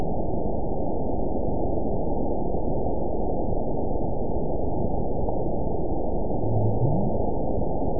event 920438 date 03/25/24 time 03:33:25 GMT (1 year, 1 month ago) score 8.55 location TSS-AB01 detected by nrw target species NRW annotations +NRW Spectrogram: Frequency (kHz) vs. Time (s) audio not available .wav